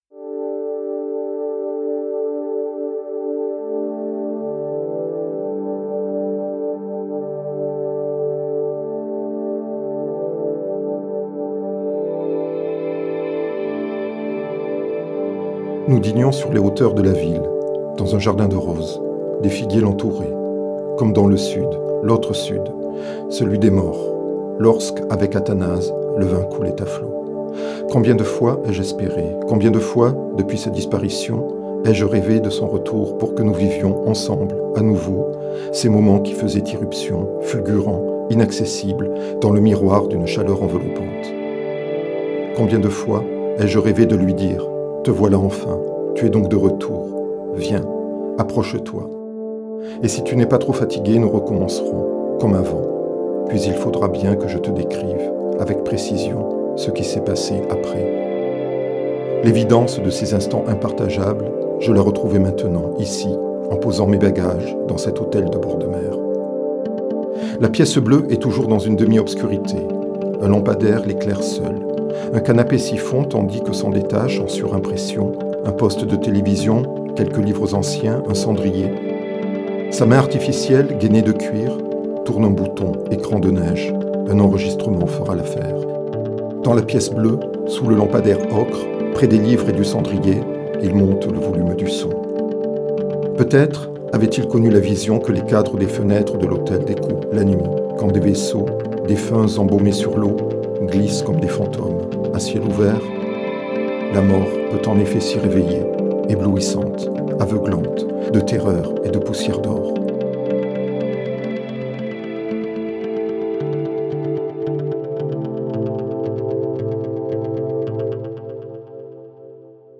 entre post-wave, minimalisme et ambient
seront cette fois mis en musique
Cette lecture est ici disponible :